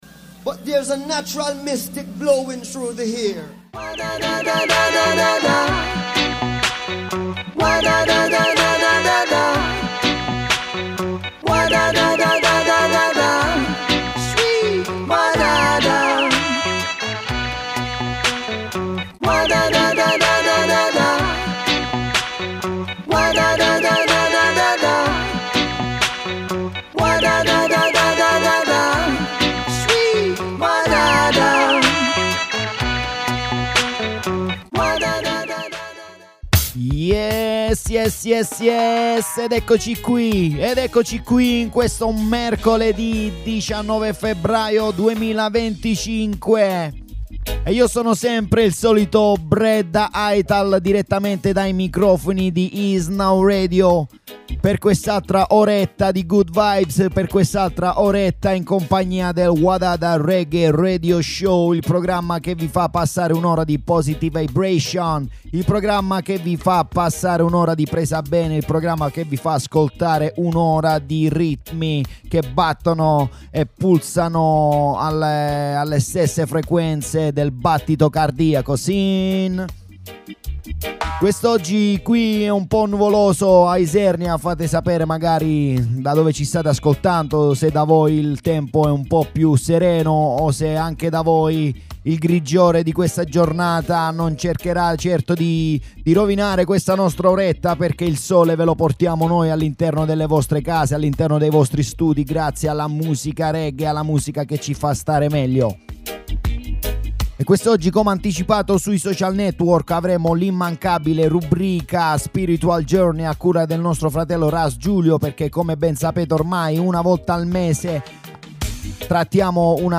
Roots Reggae Dub Music